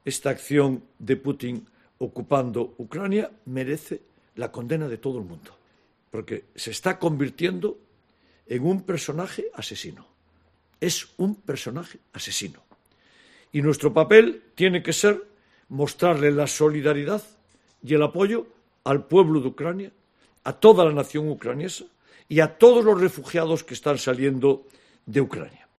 Contundente. Así se ha mostrado este viernes en rueda de prensa el alcalde de Vigo, Abel Caballero, al hablar sobre la guerra de Ucrania.